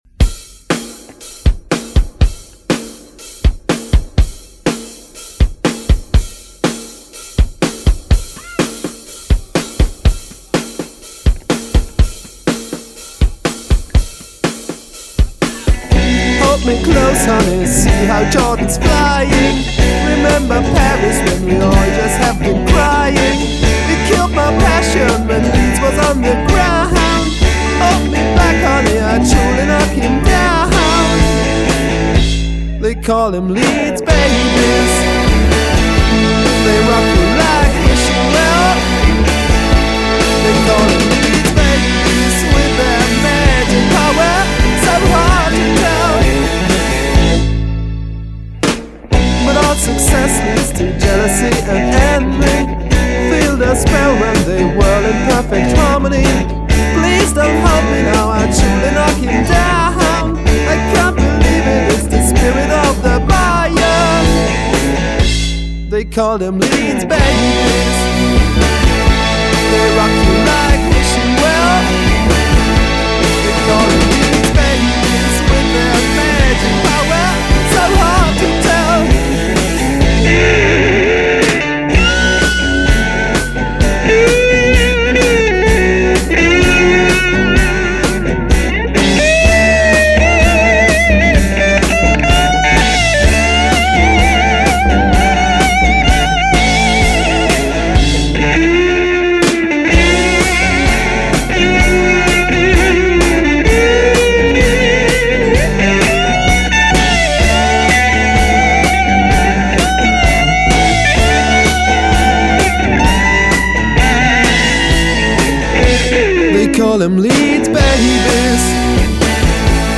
at Inter Music Studios Bottmingen
STEREO